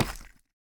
Minecraft Version Minecraft Version 1.21.5 Latest Release | Latest Snapshot 1.21.5 / assets / minecraft / sounds / block / deepslate / step3.ogg Compare With Compare With Latest Release | Latest Snapshot